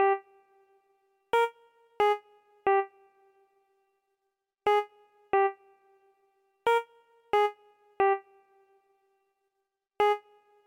90 器官 1
Tag: 90 bpm Hip Hop Loops Organ Loops 1.79 MB wav Key : Unknown